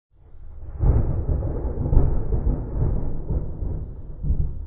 thunder.mp3